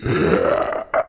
zombie-6.ogg